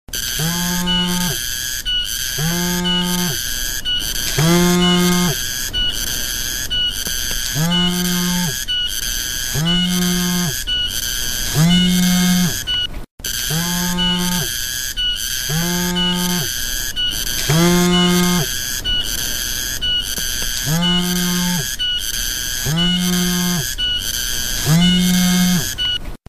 Nada Dering suara Tonggeret
Genre: Nada dering binatang Tag: nada dering binatang Ukuran file: 856 KB Dilihat: 2655 Views / 93 Downloads Detail: Suara tonggeret dari hutan pedesaan ini menghadirkan suasana alam yang adem, unik, dan pastinya bikin HP kamu terdengar keren, lucu, dan nggak pasaran.
nada-dering-suara-tonggeret.mp3